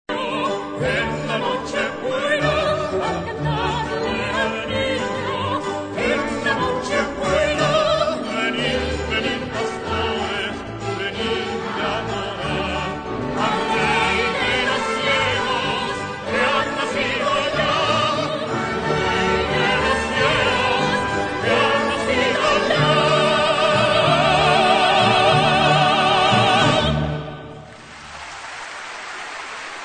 Piano & Vocal Score
key: D-major